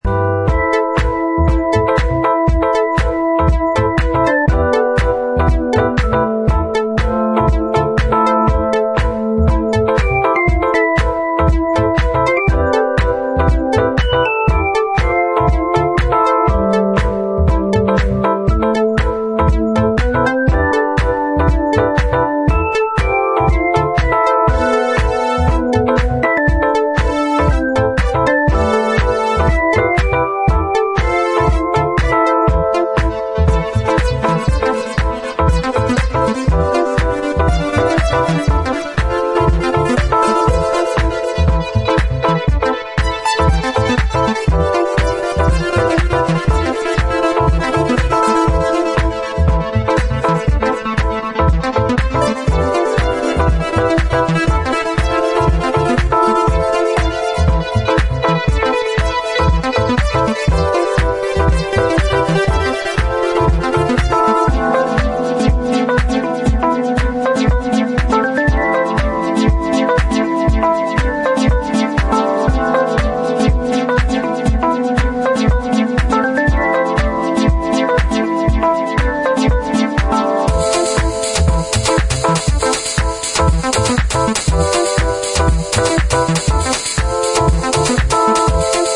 creative and inspiring techno
moody, eclectic and dancefloor friendly